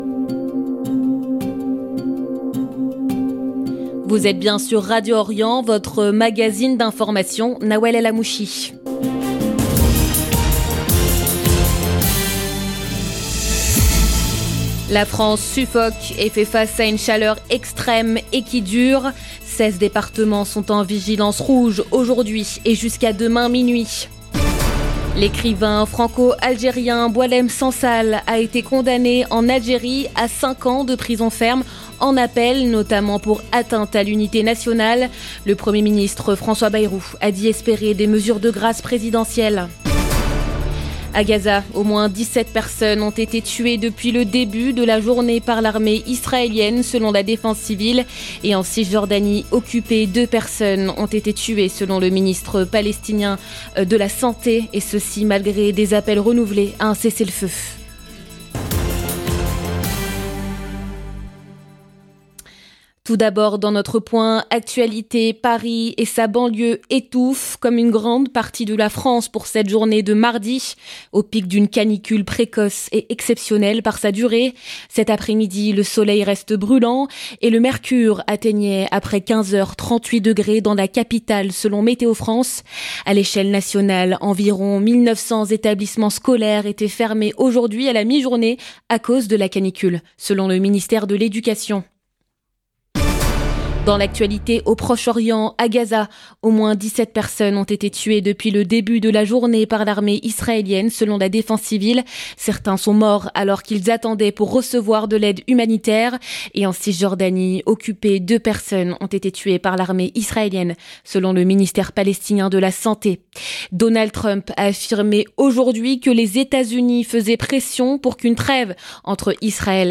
Magazine d'information de 17H00 du 1 juillet 2025